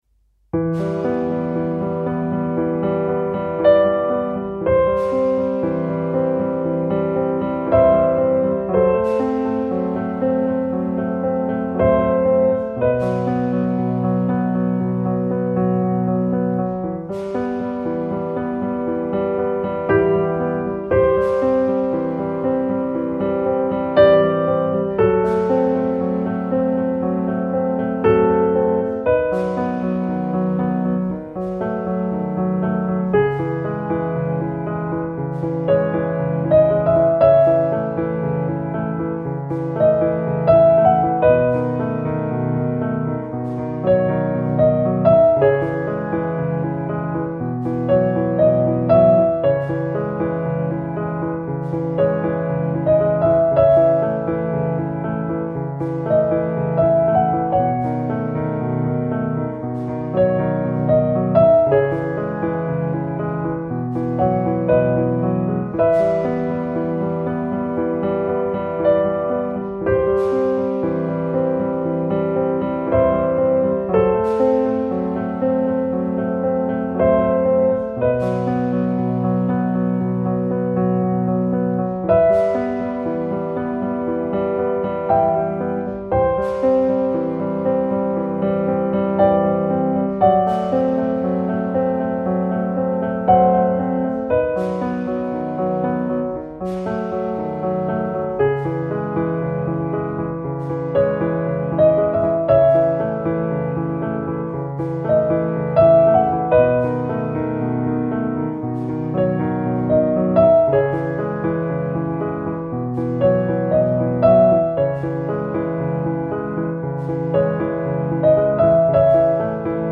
nostalgique - melodieux - calme - melancolie - piano